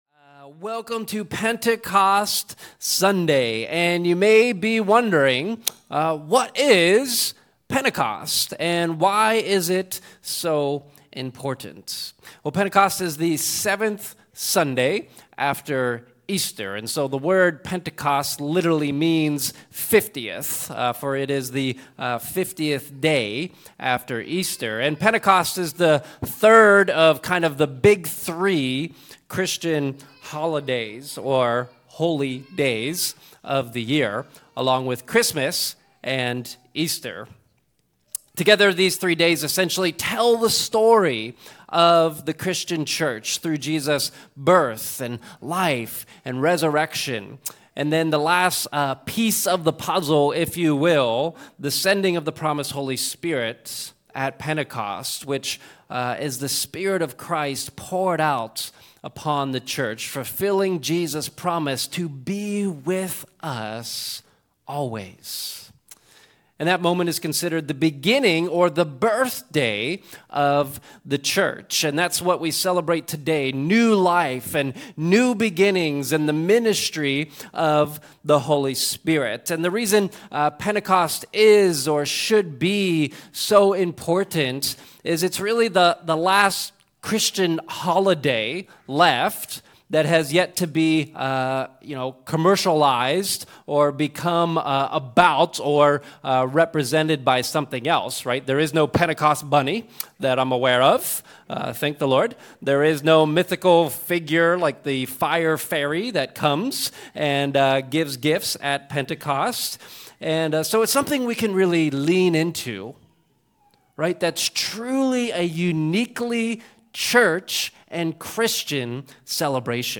We celebrate Pentecost Sunday with a message on why the post-Pentecost church was so appealing and apply it to our church and the launch of some new ministries.